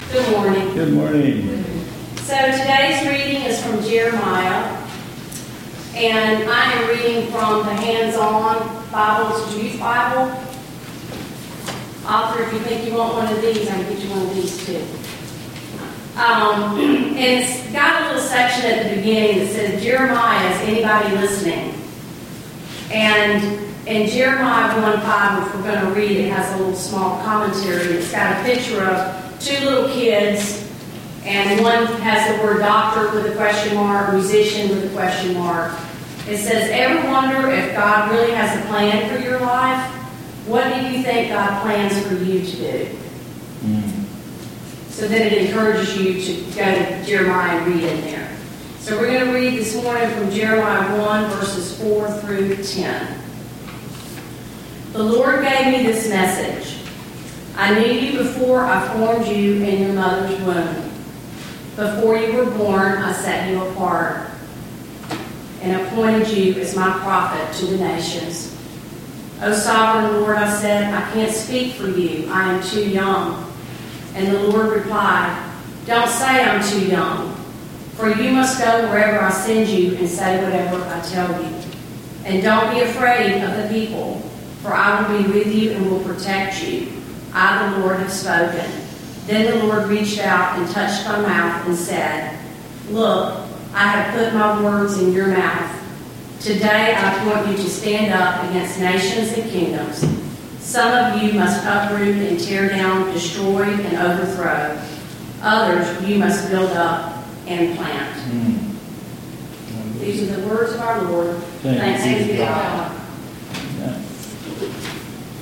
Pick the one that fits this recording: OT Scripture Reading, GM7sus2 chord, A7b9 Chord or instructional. OT Scripture Reading